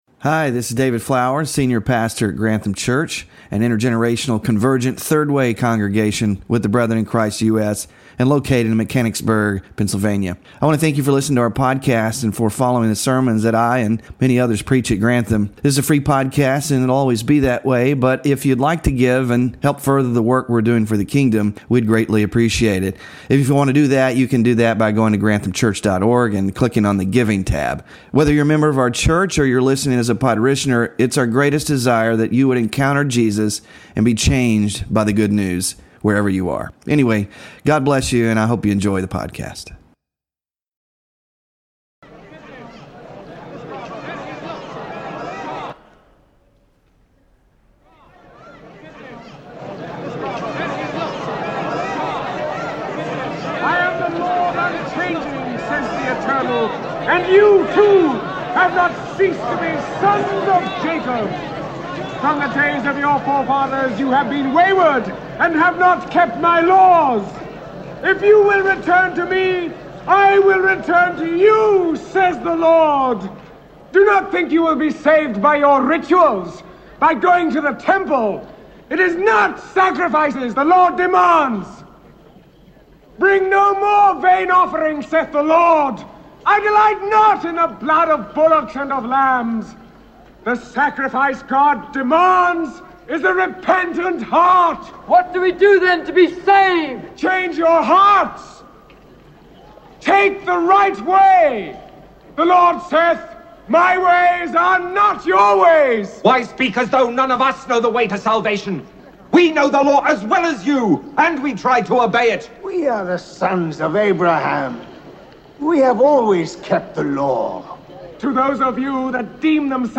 Sermon Focus